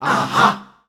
Index of /90_sSampleCDs/Voices_Of_Africa/ShortChantsShots&FX